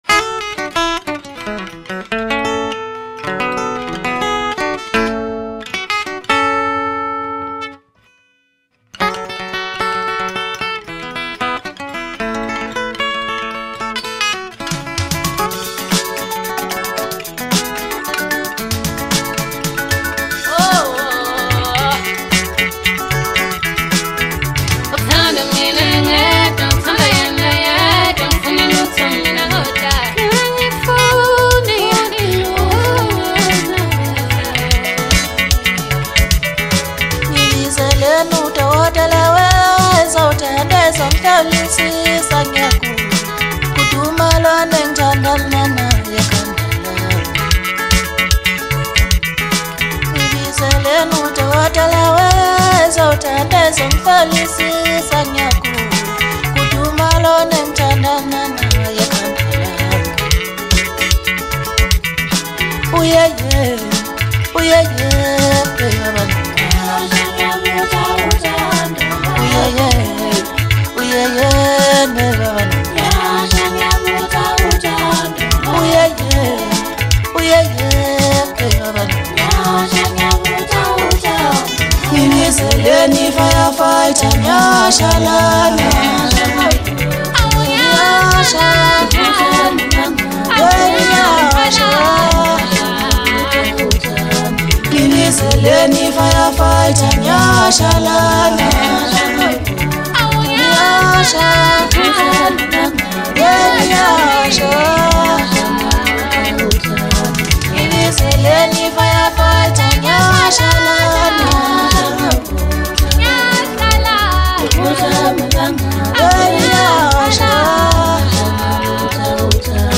Home » Deep House » Hip Hop » Maskandi
Talented vocalist